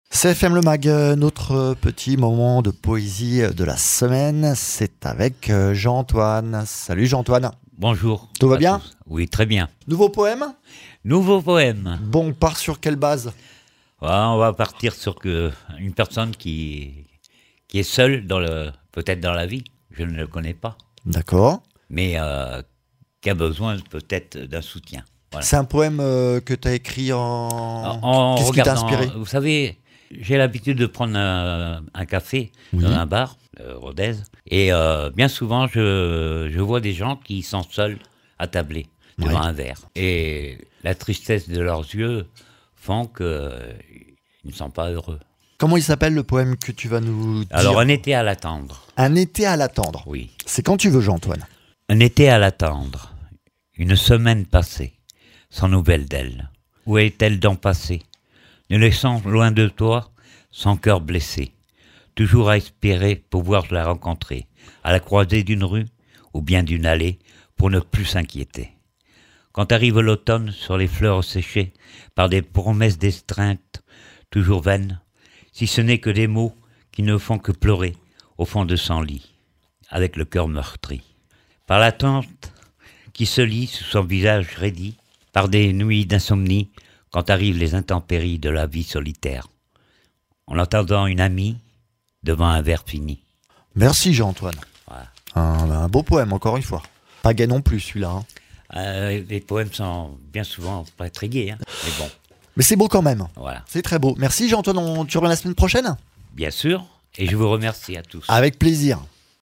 Interviews
chroniqueur poésie